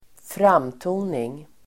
Ladda ner uttalet
Uttal: [²fr'am:to:ning]